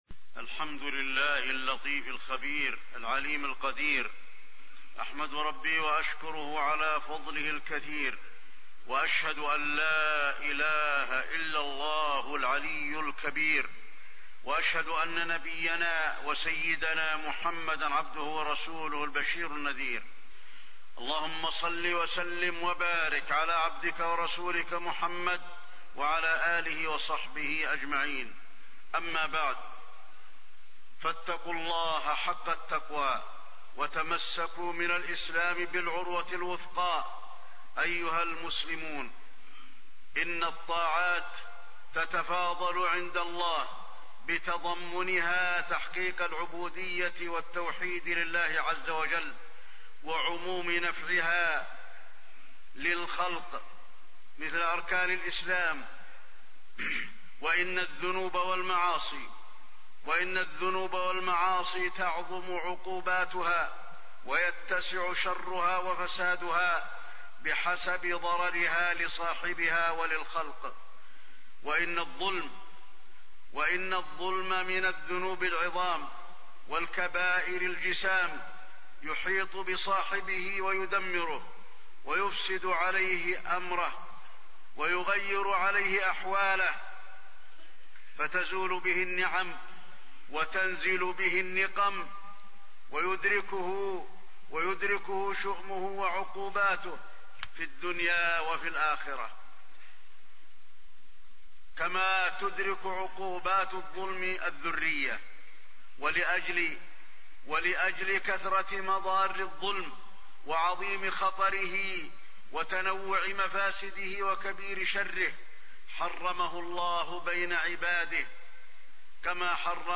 تاريخ النشر ١٧ جمادى الآخرة ١٤٣٢ هـ المكان: المسجد النبوي الشيخ: فضيلة الشيخ د. علي بن عبدالرحمن الحذيفي فضيلة الشيخ د. علي بن عبدالرحمن الحذيفي عواقب الظلم